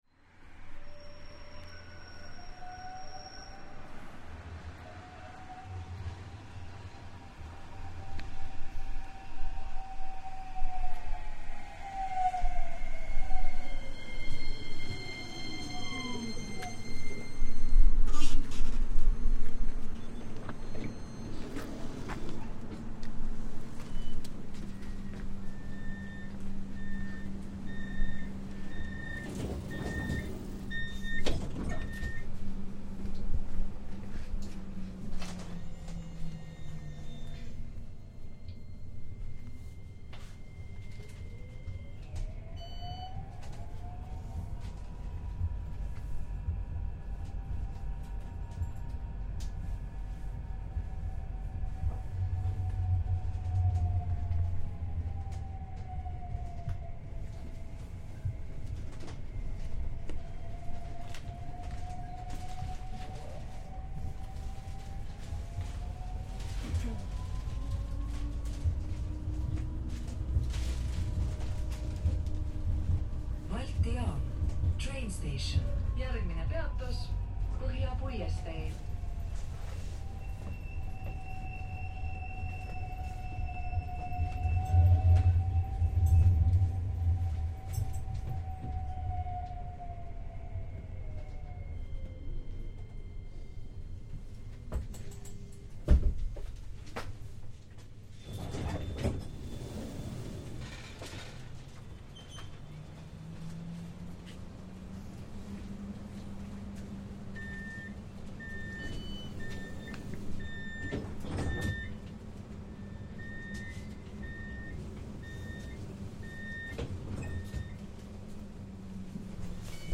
A ride from the trendy cultural hub of Telliskivi in Tallinn to Kanuti aboard the ubiquitous trams - all public transport is completely free to residents of Tallinn.
Here we listen to the sounds of the tram journey, complete with announcements, doors sounds, exiting the tram and listening to it depart.